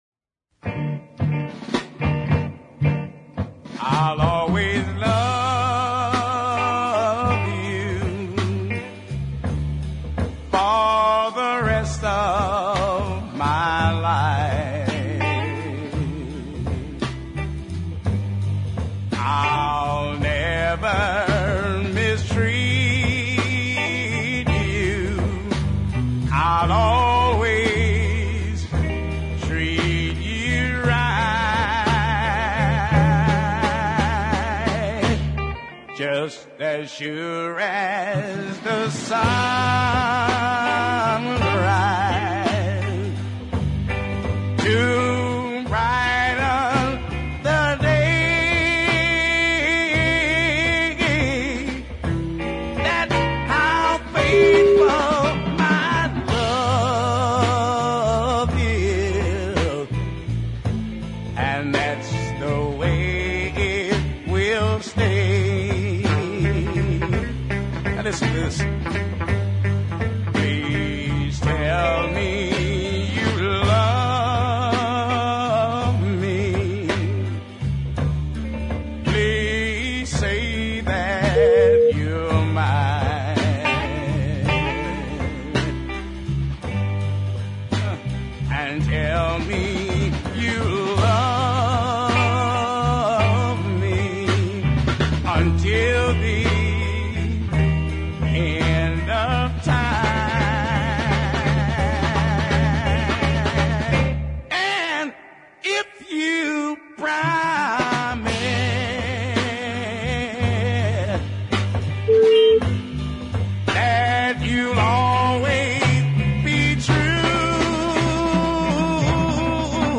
blues ballad of considerable power